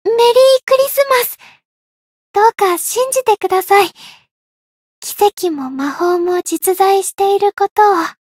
灵魂潮汐-伊汐尔-圣诞节（相伴语音）.ogg